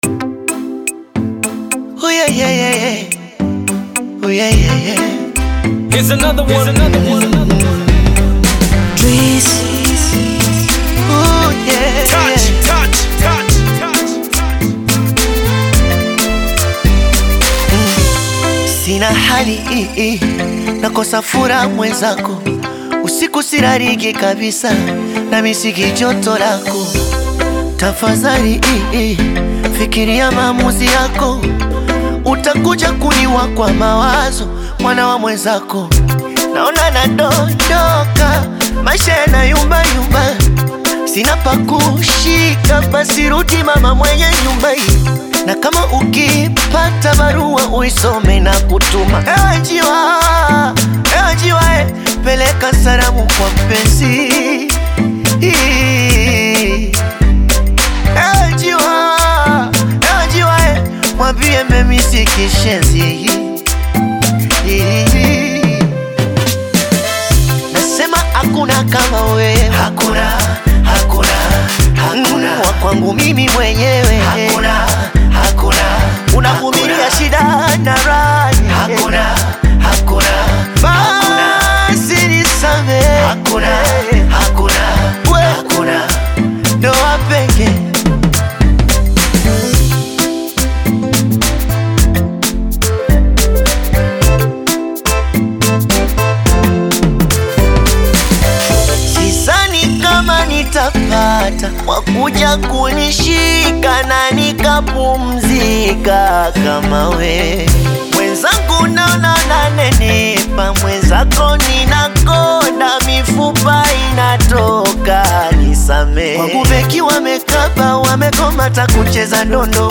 With expressive vocals and catchy melodies
Genre: Bongo Flava